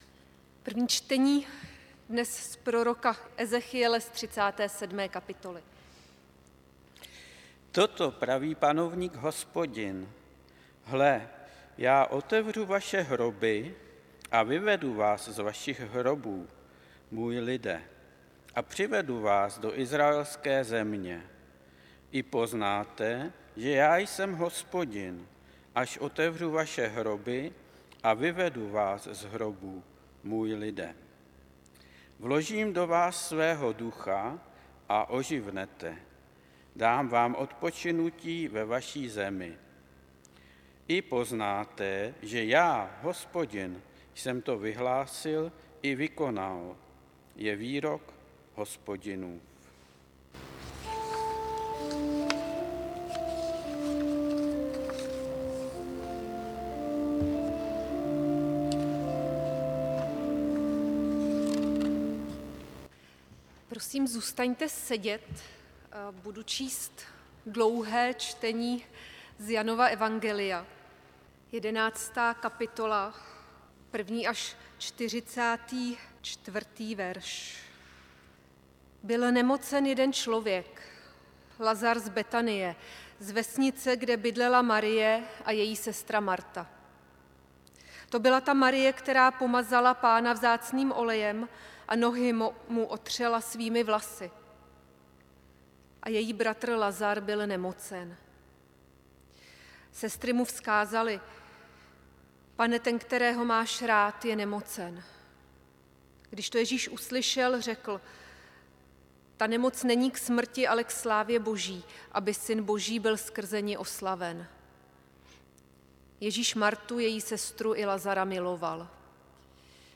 5. neděle postní – bohoslužby a VSS – 22. března 2026
záznam kázání Janovo evangelium 11, 1 – 44